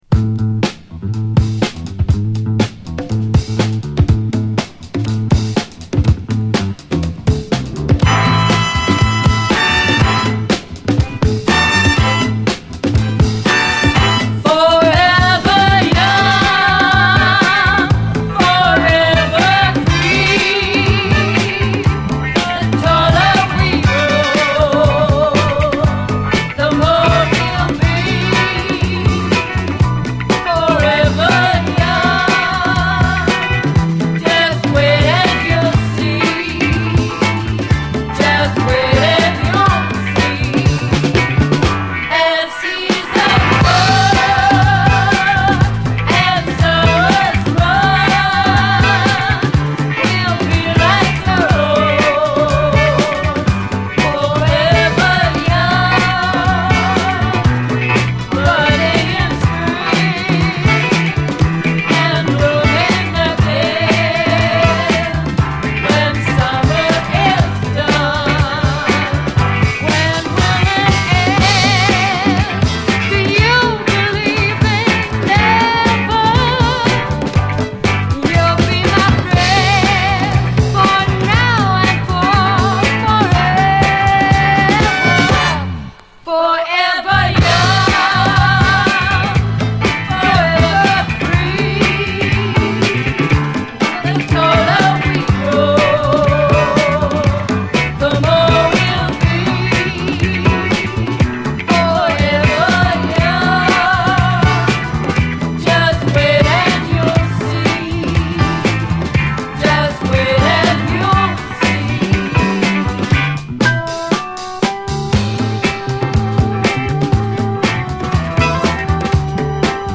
こちらもスペイシーでメロウな世界観に酔いしれる、内容最高のレアグルーヴ〜ジャズ・ファンク名盤！
銀河間を交信できそうなくらいに美しくスペイシーなLOFT的トラック！